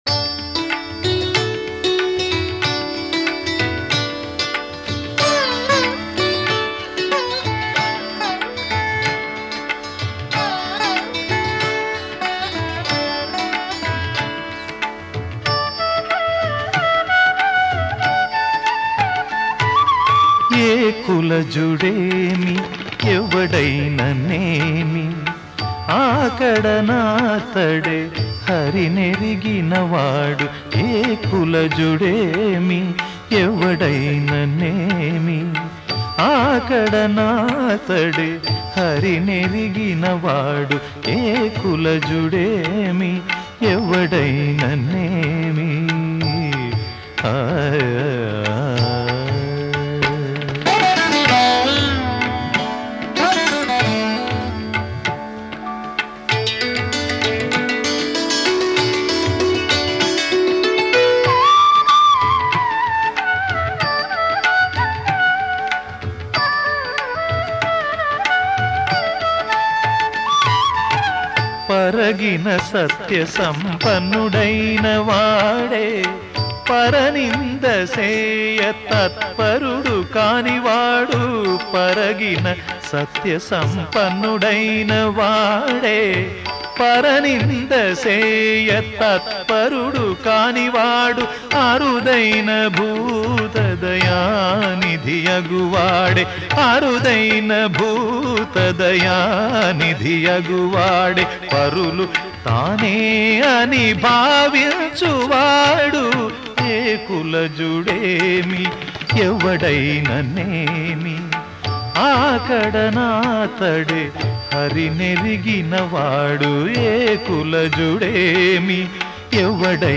సంకీర్తన